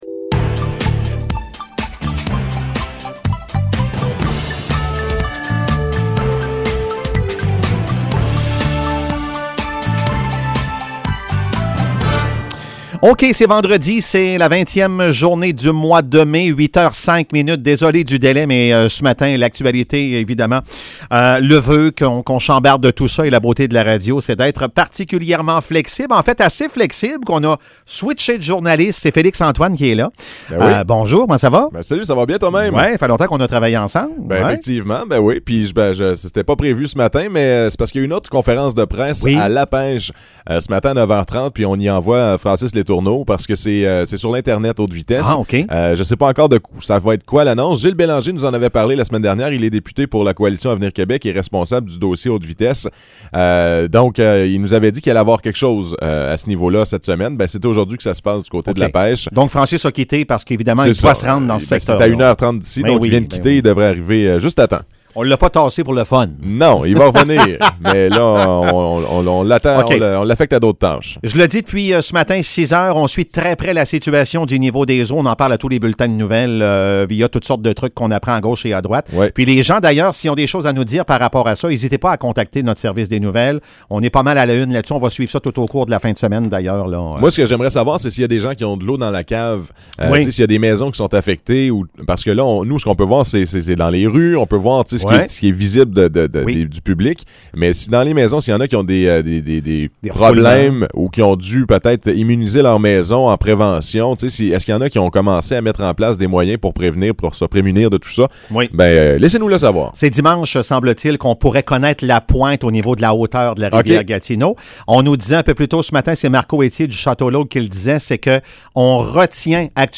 Nouvelles locales - 20 mai 2022 - 8 h